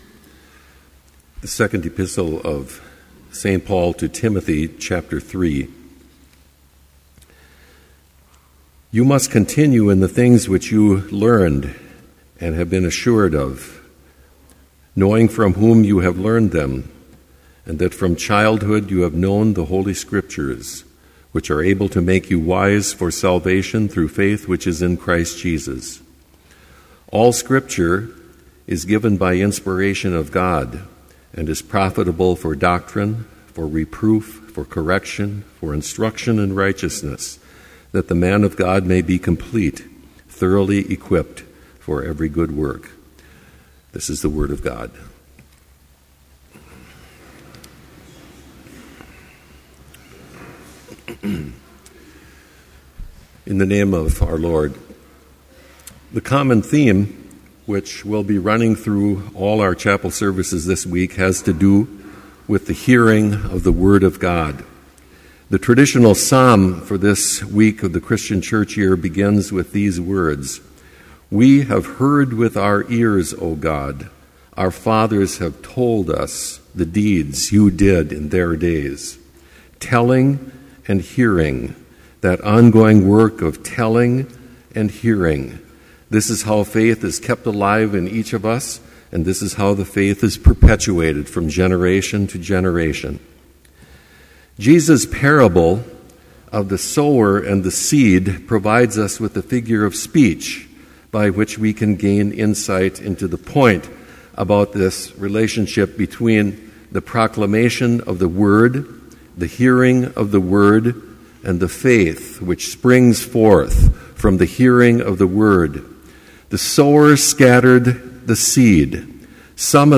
Complete Service
• Prelude
• Hymn 229, vv. 1-3, By Faith We Are Divinely Sure
• Homily
This Chapel Service was held in Trinity Chapel at Bethany Lutheran College on Monday, February 4, 2013, at 10 a.m. Page and hymn numbers are from the Evangelical Lutheran Hymnary.